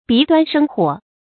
鼻端生火 注音： ㄅㄧˊ ㄉㄨㄢ ㄕㄥ ㄏㄨㄛˇ 讀音讀法： 意思解釋： 以之形容馬行疾速。